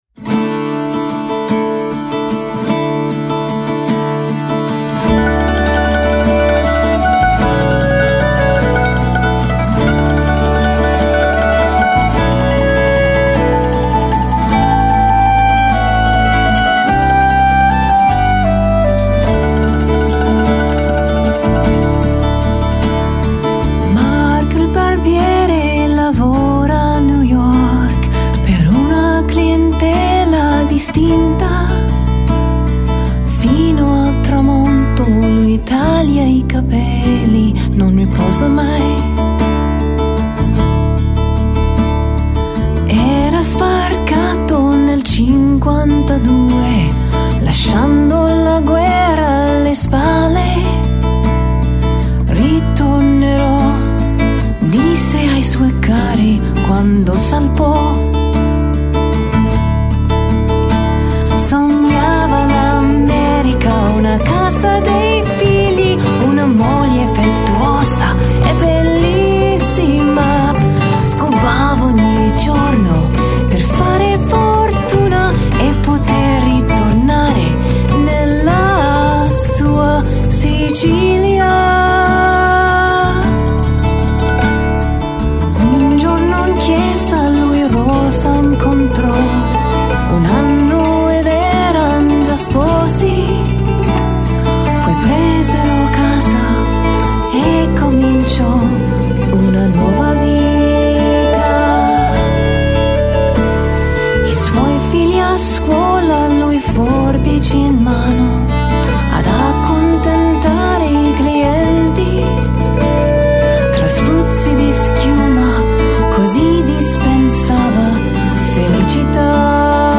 vocals, clarinet
guitar
mandolin
accordion
electric bass
percussion